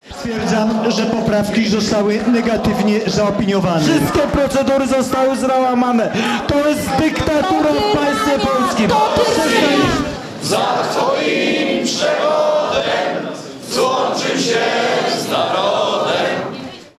obrady komisji.mp3